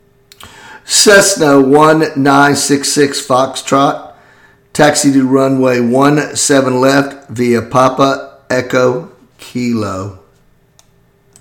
Aviation Radio Calls
06_GroundRunwayOneSevenLeftViaPapaEchoKilo.mp3